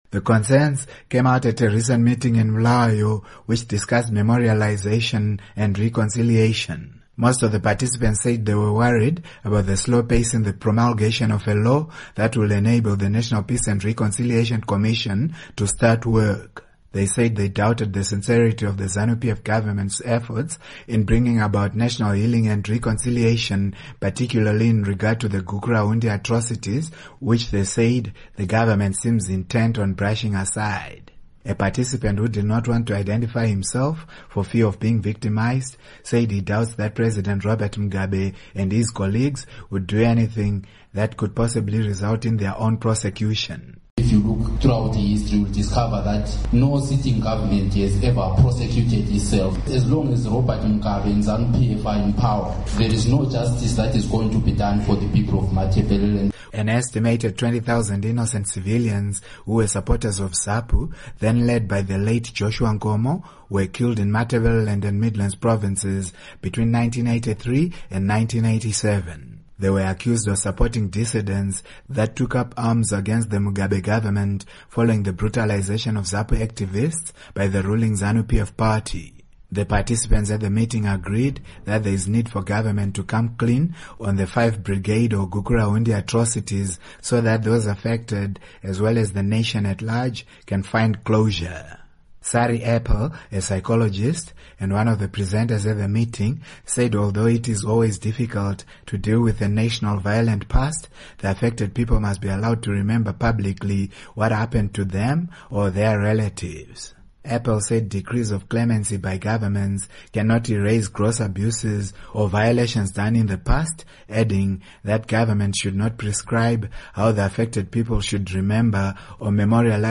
The concerns came out at a recent meeting in Bulawayo, which discussed memorialization and reconciliation.
Report on Five Brigade Atrocities, Reconciliation